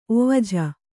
♪ ovajha